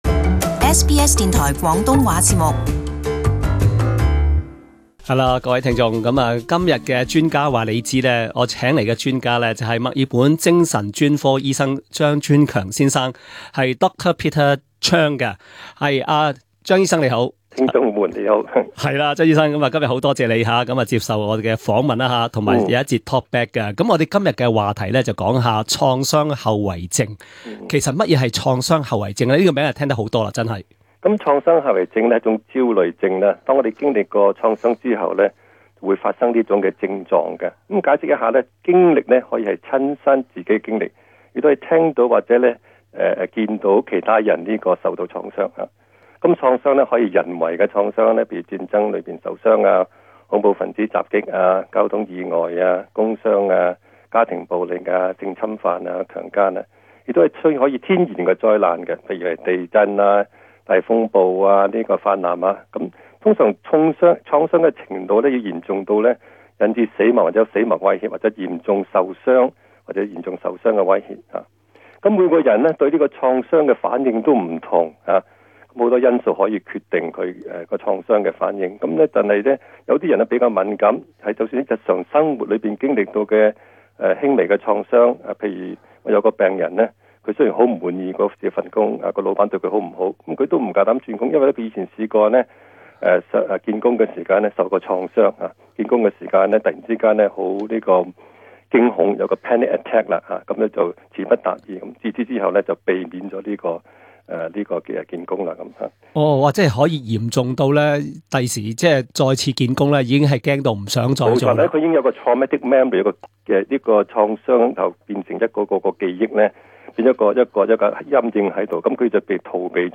Talkback: Post-traumatic stress disorder (PTSD)